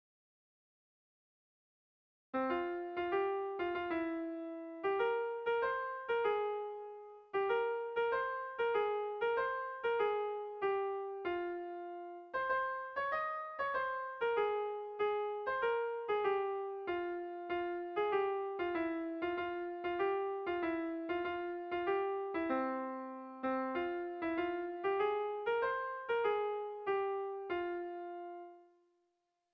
1900 urtean Parisko Erakusketa Unibertsalean egindako grabaketan -ezagutzen dugun lehen grabaketan jasotako doinua.
Grabaketa ez da oso garbia eta badira zalantzak bertsoari buruz.